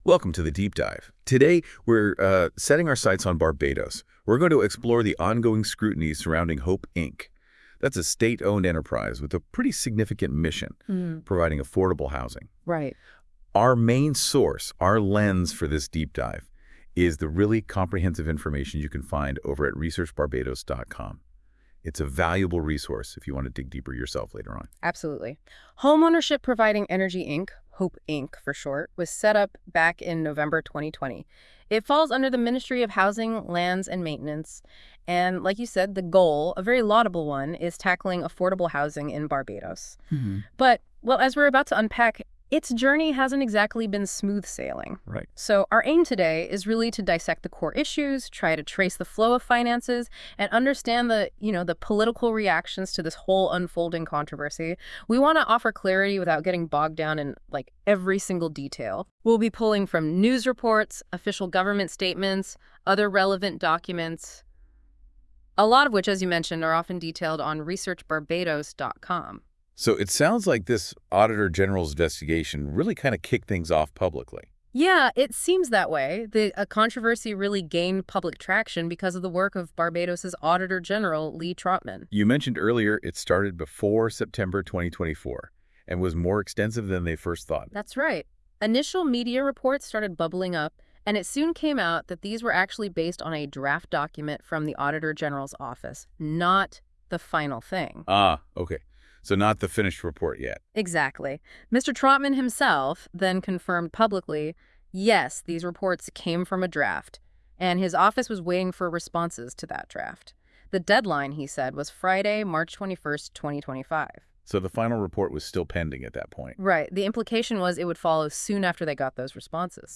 Exclusive expert commentary on Barbados' affordable housing initiative controversy